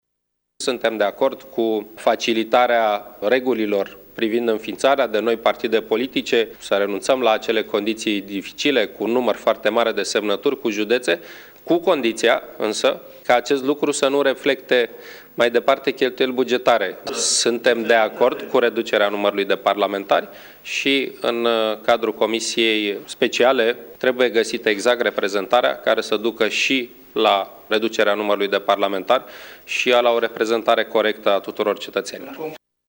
Preşedintele PSD, Victor Ponta :